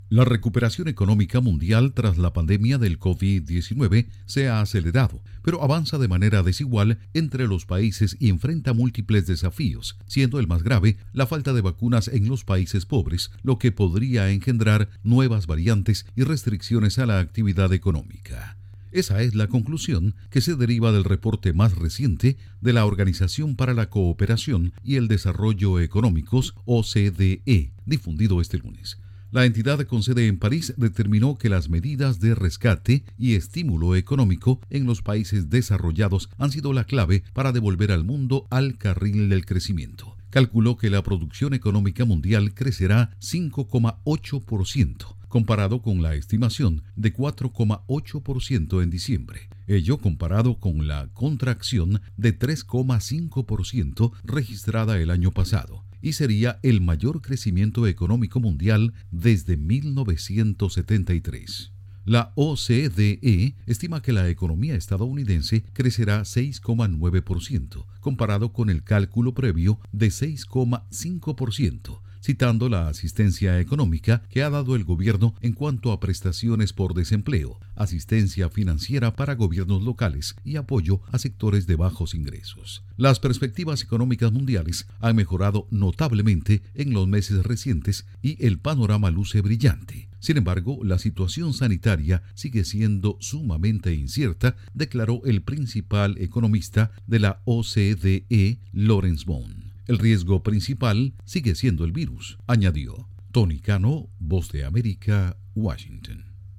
OCDE: Economía mundial se recupera, pero enfrenta desafíos. Informa desde la Voz de América en Washington